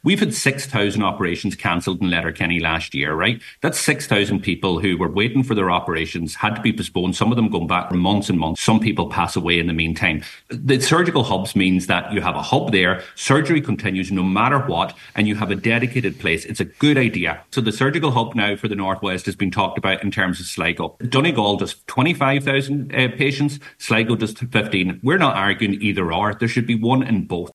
On today’s Nine til Noon Show, Deputy Pearse Doherty repeated that Letterkenny University Hospital continuously punches above its weight, and its funding does not recognise the level of work done there.